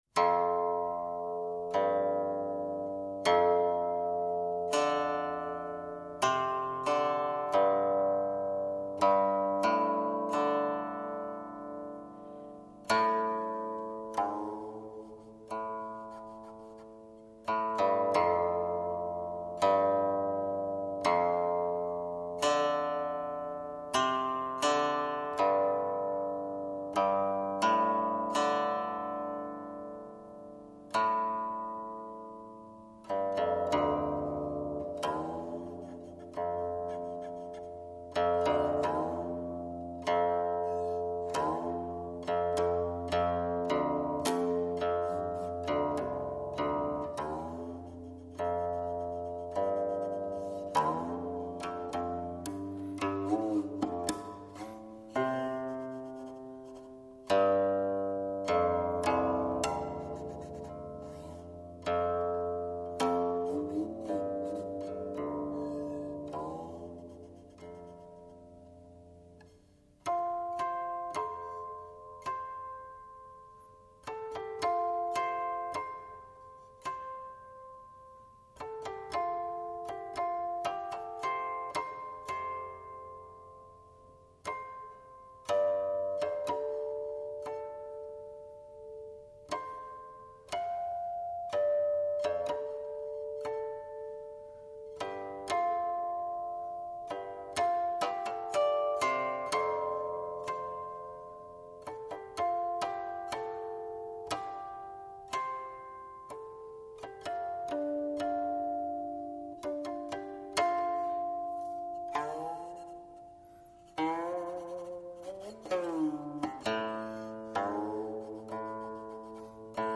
类型：古琴